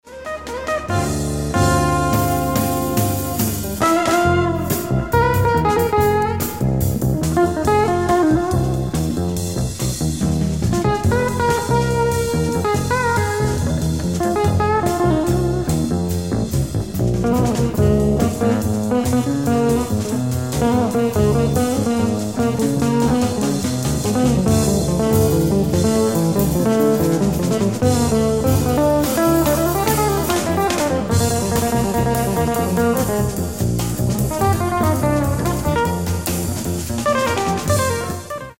ライブ・アット・厚生年金会館、東京 01/28/1992
※試聴用に実際より音質を落としています。